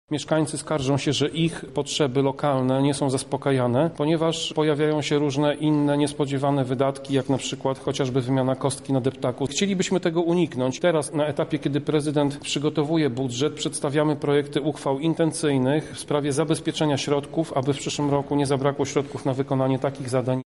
– Są to prośby, które wnoszą do nas mieszkańcy – tłumaczy Tomasz Pitucha, przewodniczący klubu radnych Prawa i Sprawiedliwości w Lublinie: